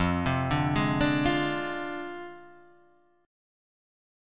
F Minor 6th